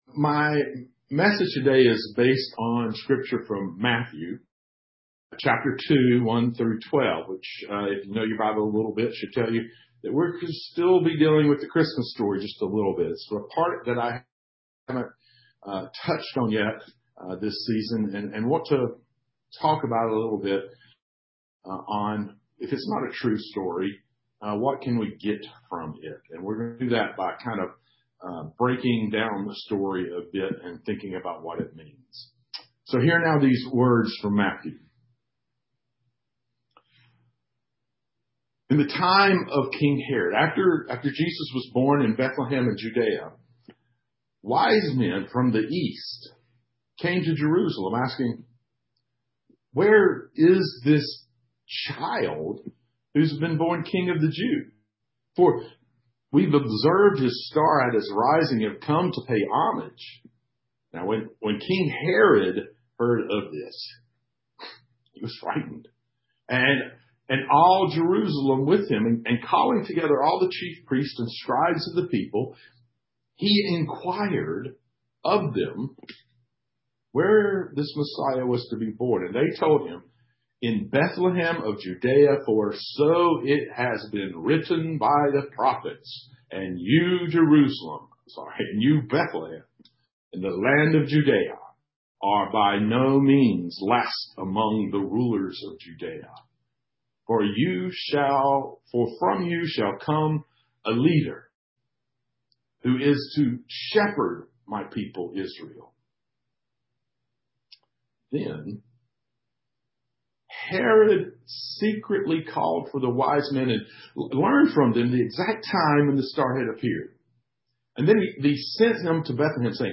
Matthew 2:1-12 (streamed via Zoom and Facebook)